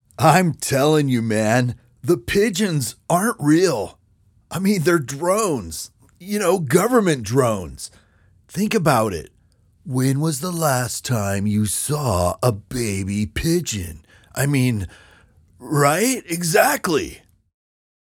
Animation - Conspiracy Demo
North American English, British (general)
- Professional recording studio and analog-modeling gear